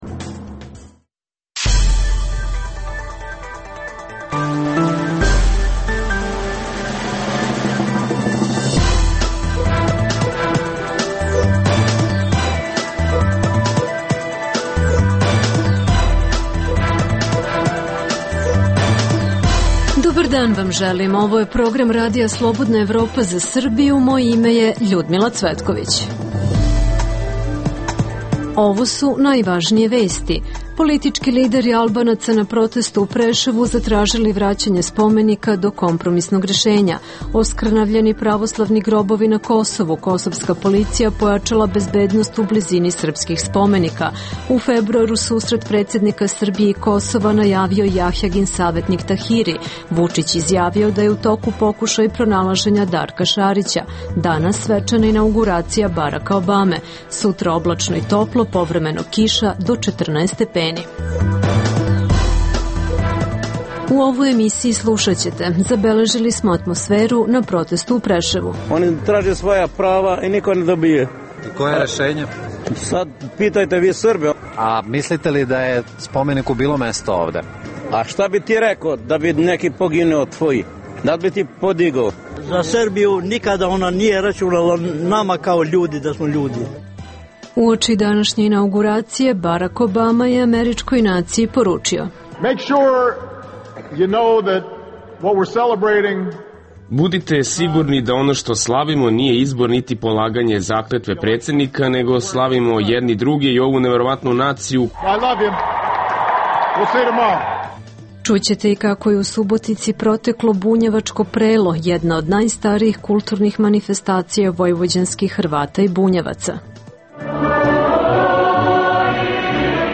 - Izveštavamo sa protesta u Preševu zbog uklanjanja spomenika borcima OVPBM. Čućete učesnike protesta i čelnike političkih partija i nevladinih organizacija.